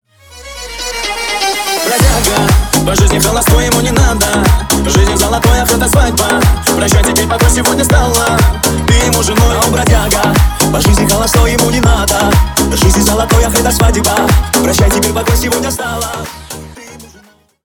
Ремикс # Танцевальные
ритмичные